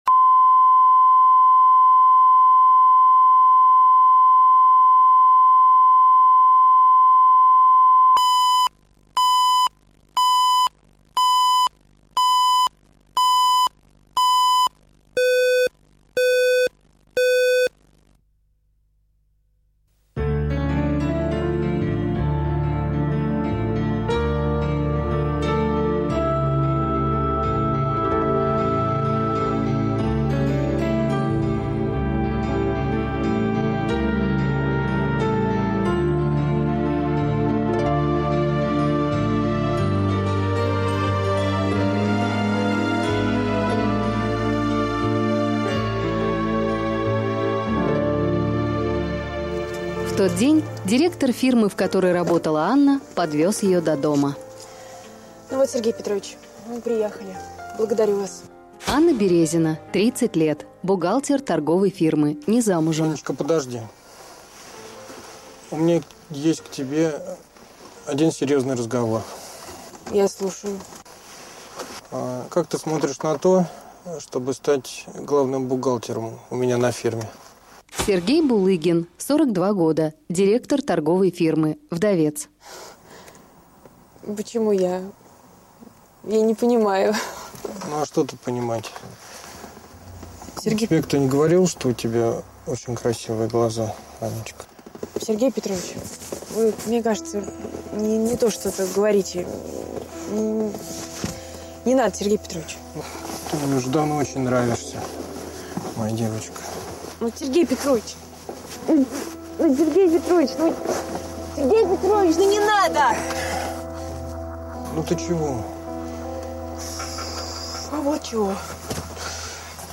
Аудиокнига Ее мать | Библиотека аудиокниг